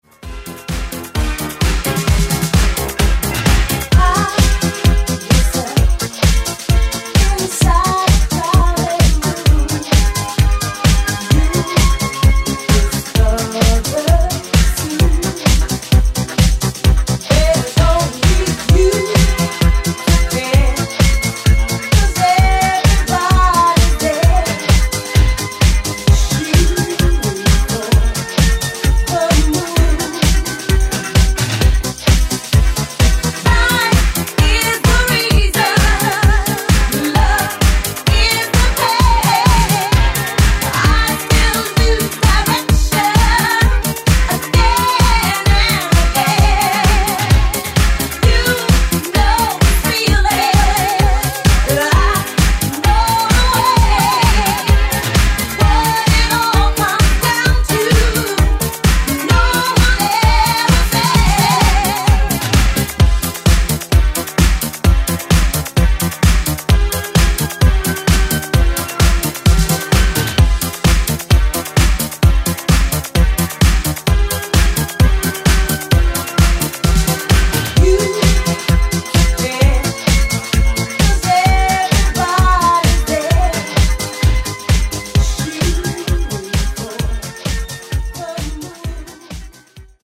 Genres: 80's , RE-DRUM
Clean BPM: 130 Time